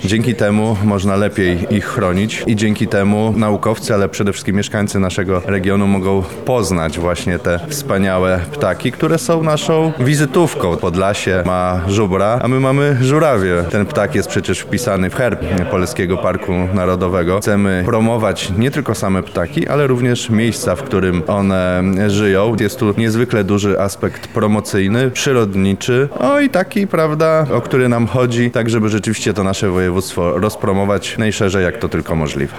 Dzisiaj (8 listopada) miała miejsce konferencja prasowa podsumowująca dotychczasowe działania tego projektu.
– mówi Marek Wojciechowski, wicemarszałek województwa lubelskiego.